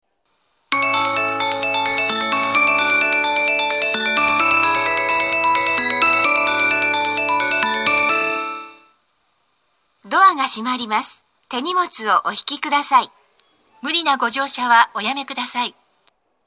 B線ホームで収録すると、真上にA線の線路があるのでよくガタンゴトンと被ります・・。
到着放送